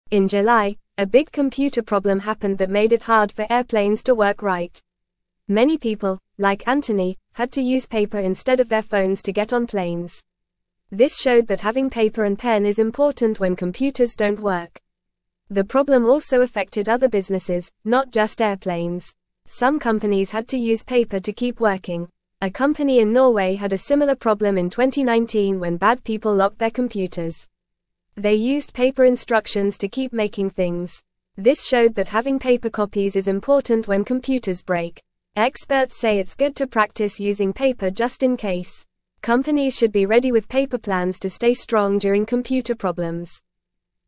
Fast
English-Newsroom-Beginner-FAST-Reading-Paper-is-important-when-computers-dont-work.mp3